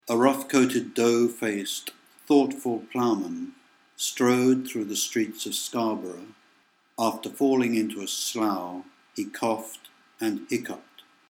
Вы найдете все девять видов чтения этого сочетания в данном предложении: